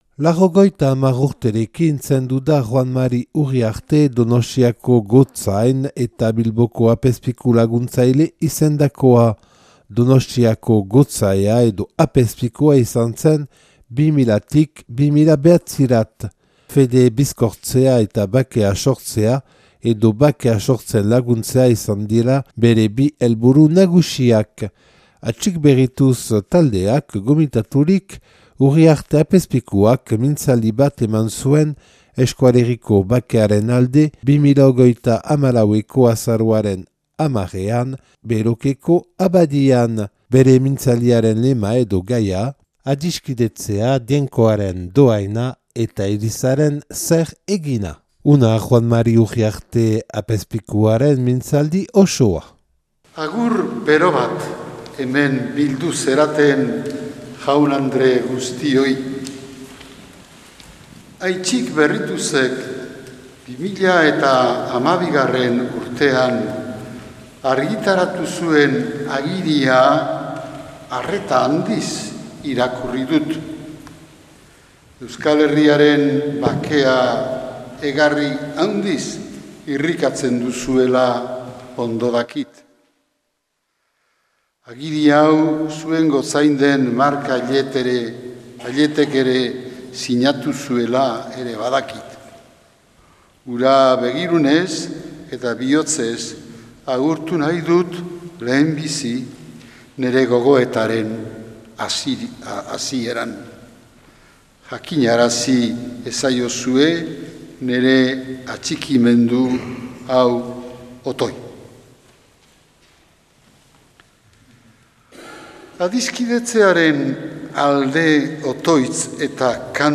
Juan Mari Uriarte, Donostiako apezpikua ohia. Atxik Berrituz taldeak gomitaturik 2013ko azaroaren 10ean, Belokeko abadian.